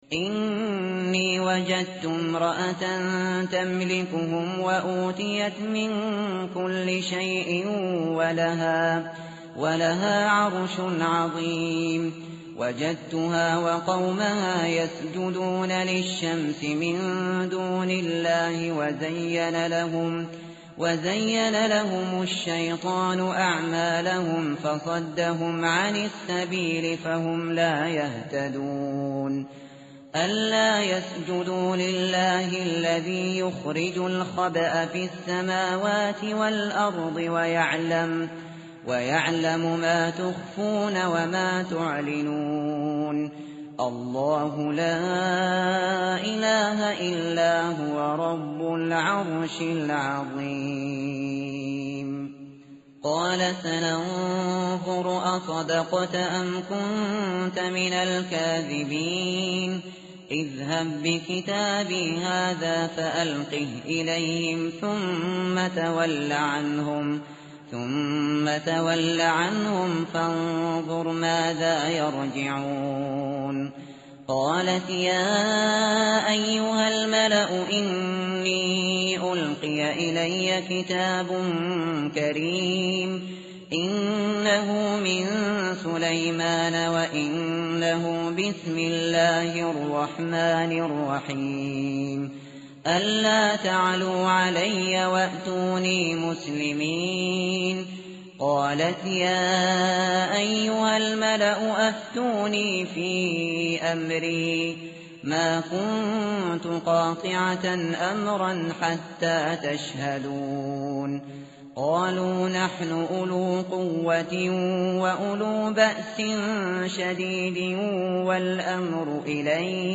متن قرآن همراه باتلاوت قرآن و ترجمه
tartil_shateri_page_379.mp3